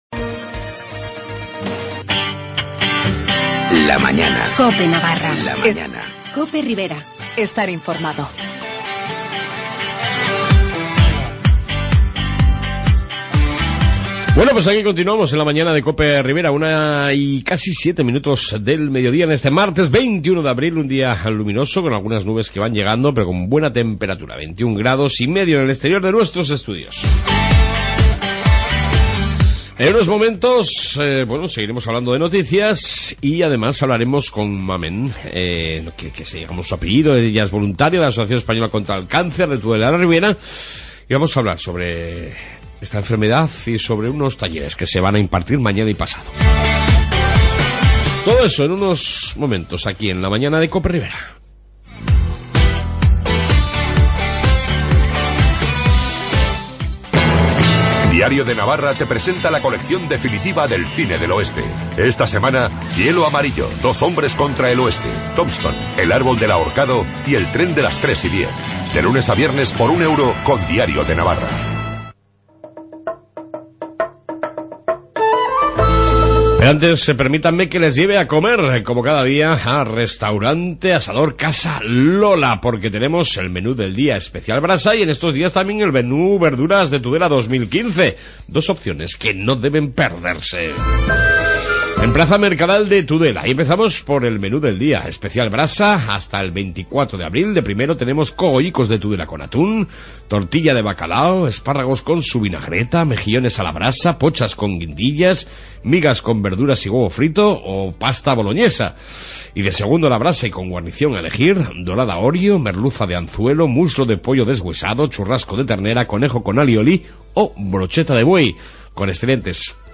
AUDIO: En esta 2 parte Informativo ribero y entrevista sobre talleres a realizar por la Asociación española contra el Cancer de Tudela y la Ribera